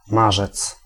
Ääntäminen
Ääntäminen France: IPA: [maʁs] Tuntematon aksentti: IPA: /maʁ/ Haettu sana löytyi näillä lähdekielillä: ranska Käännös Ääninäyte Substantiivit 1. marzec {m} Suku: m .